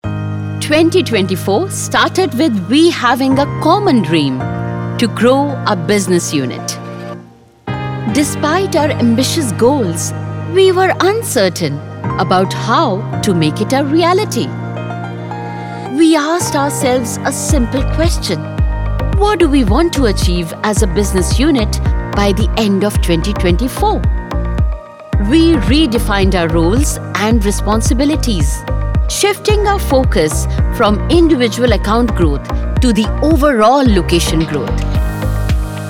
Vídeos Corporativos
Equipamento de estúdio em casa
Mic: Shure SM58
AltoSoprano